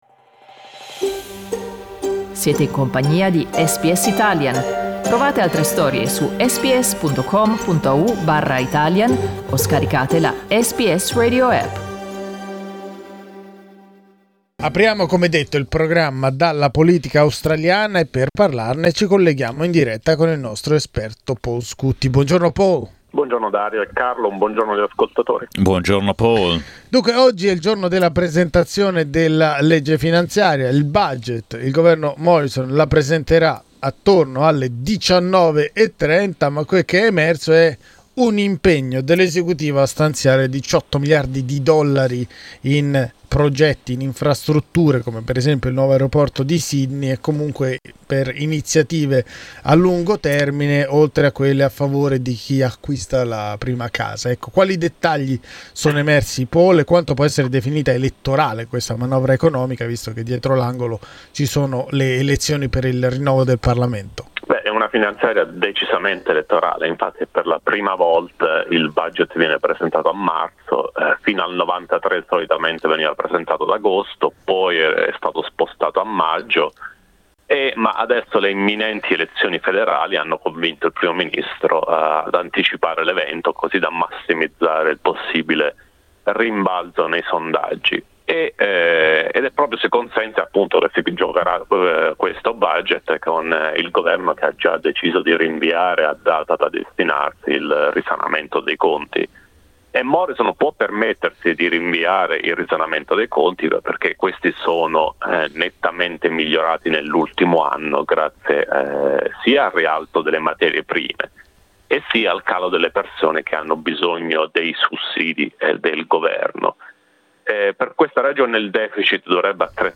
l'esperto di politica federale